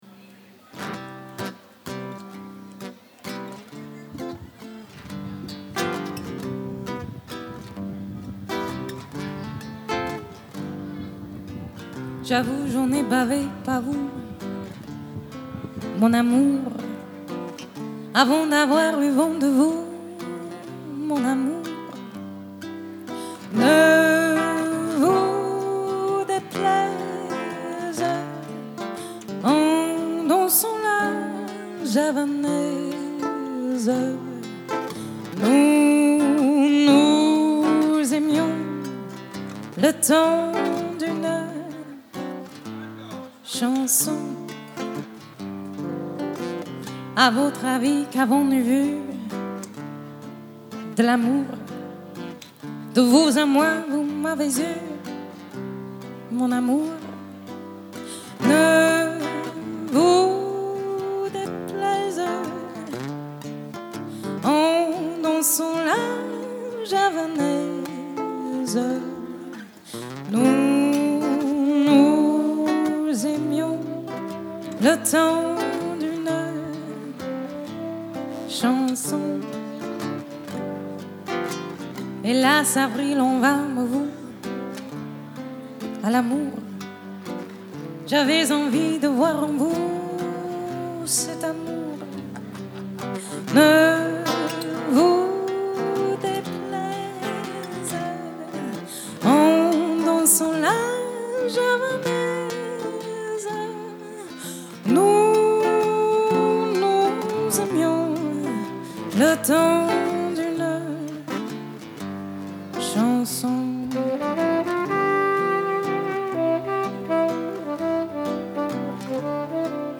French American Swing
and French/American guitar player
Reed player
tight rhythms